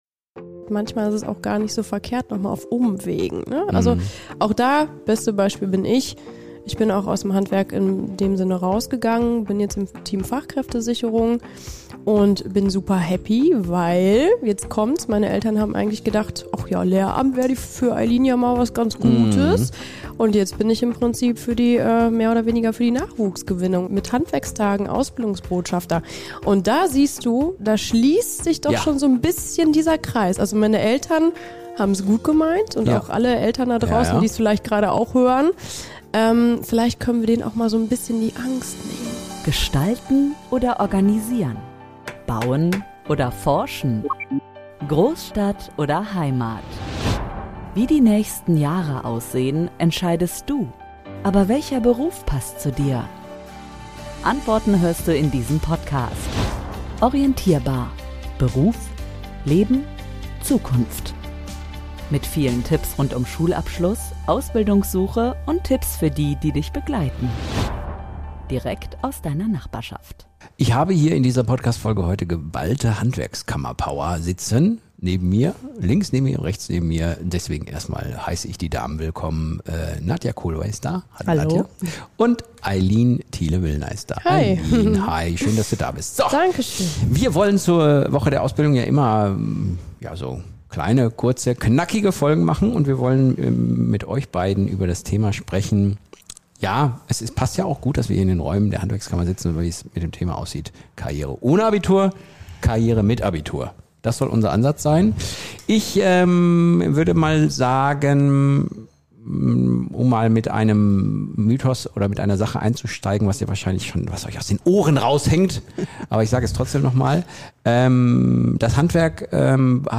Die beiden geben persönliche Einblicke in ihre eigenen Berufswege, räumen mit alten Vorurteilen auf und zeigen, wie vielfältig die Möglichkeiten im Handwerk sind.